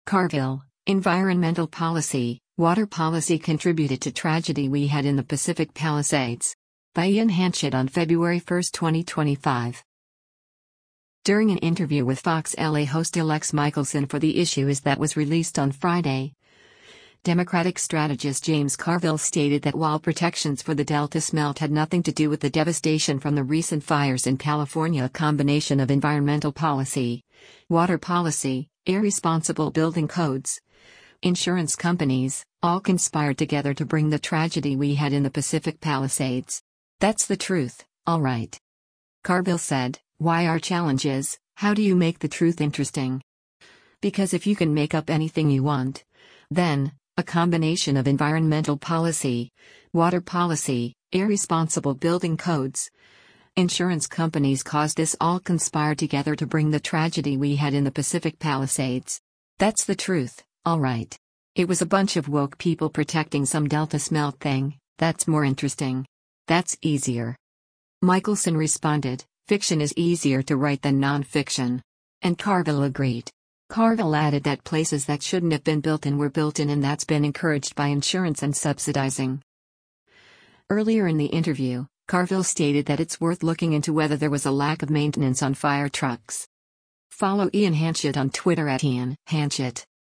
During an interview